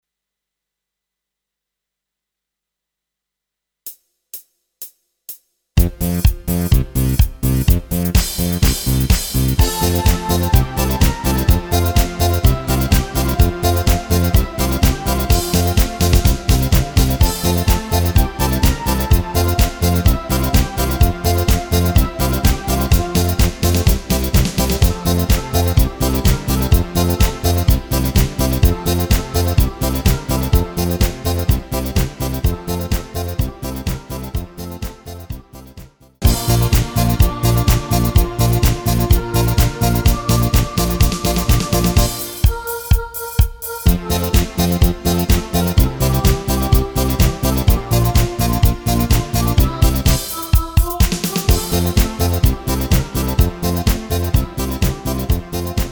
Rubrika: Pop, rock, beat
Karaoke
ROZŠÍRENÉ DEMO MP3 V PRÍLOHE